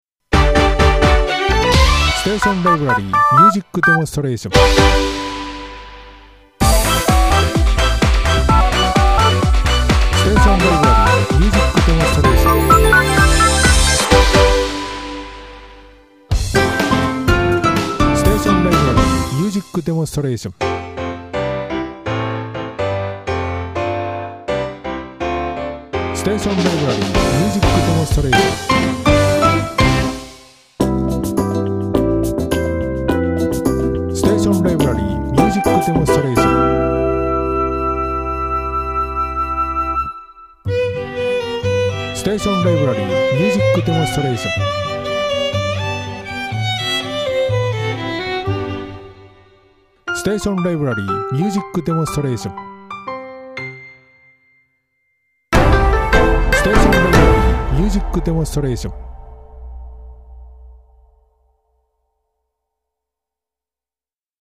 5秒から11秒の短いジングル、アタック、ブリッジの特集です。全８曲。